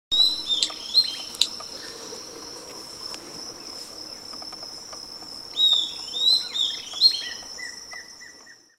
Estalador (Corythopis delalandi)
Nome em Inglês: Southern Antpipit
Detalhada localização: Parque Provincial Teyú Cuaré
Condição: Selvagem
Certeza: Gravado Vocal
Mosquitero.mp3